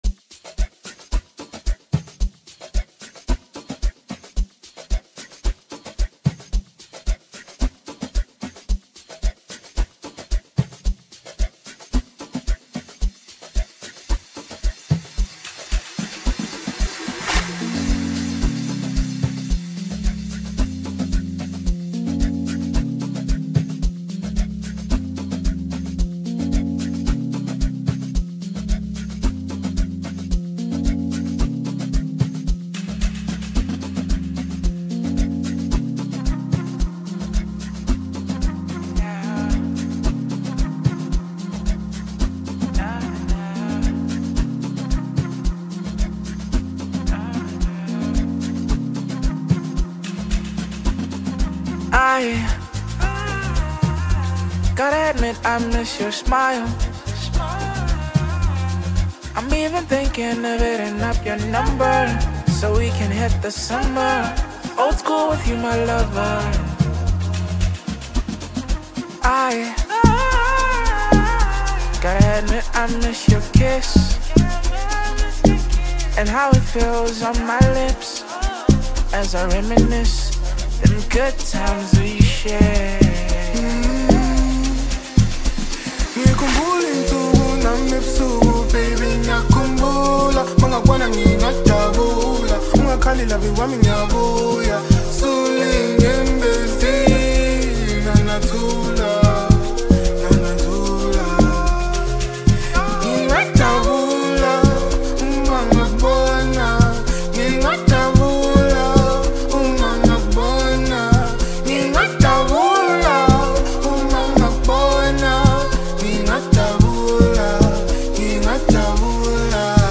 a song filled with positive energy and vibes